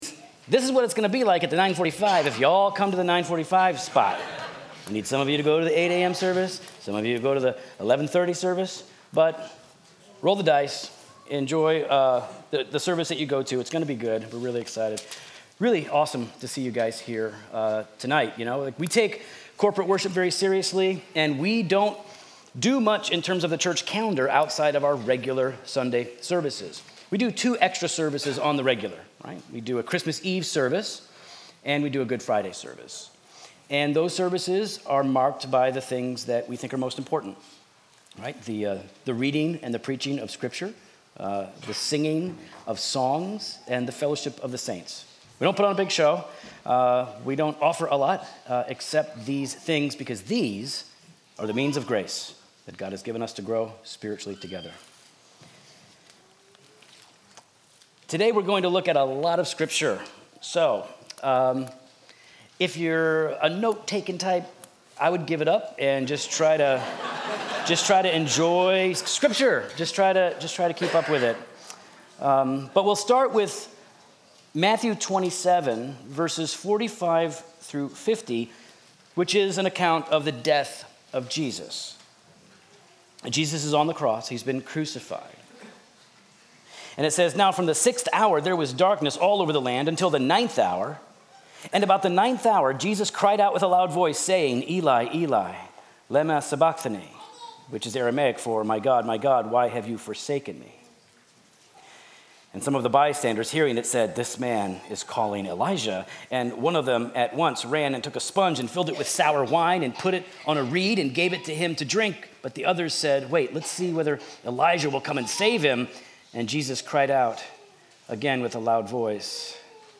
Sermon text: Matthew 27:45-50